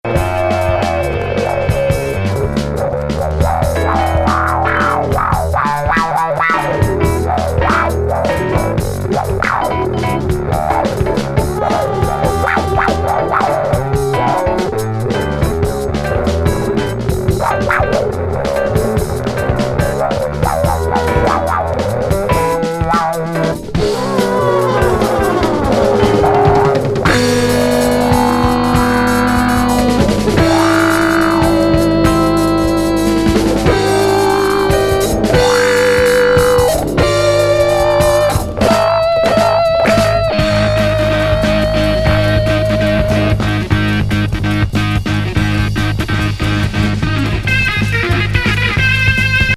ドリーミー・サイケ感の漂うジャジー・